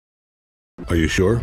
Instant meme sound effect perfect for videos, streams, and sharing with friends.